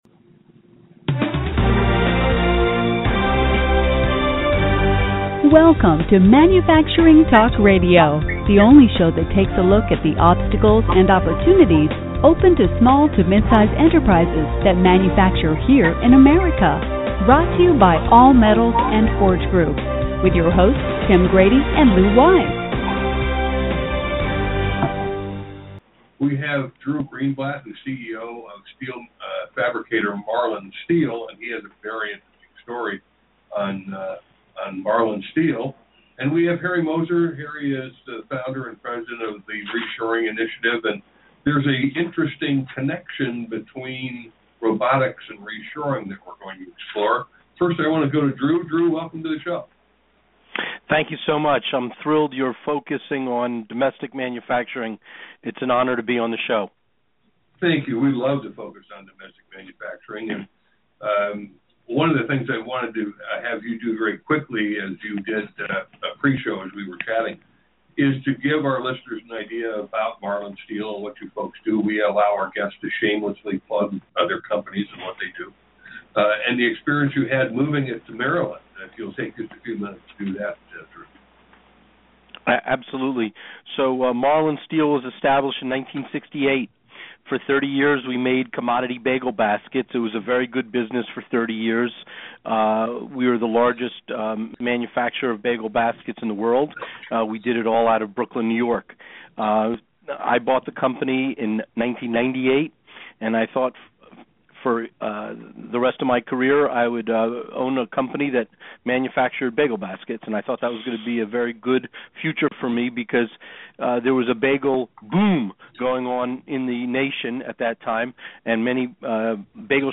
Marlin_Steel_Manufacturing_Interview_1_20.mp3